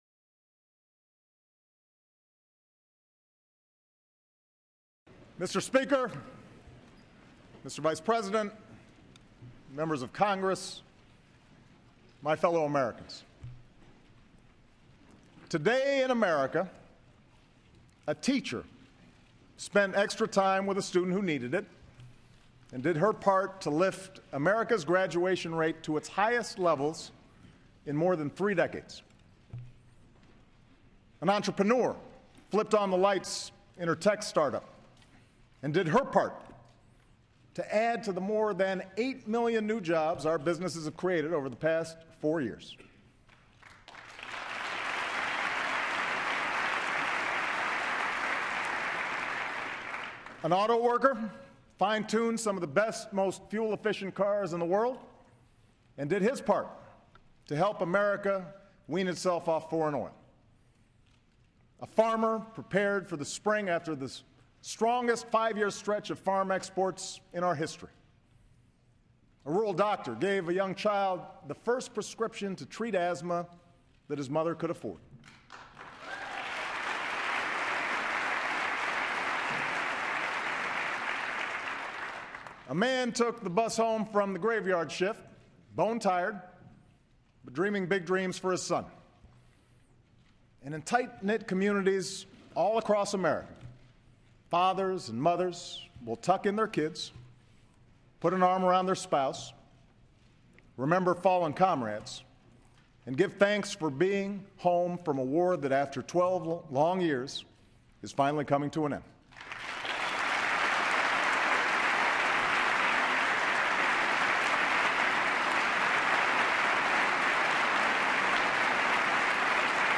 President Obama delivers the 2014 State of the Union address to Congress and the nation.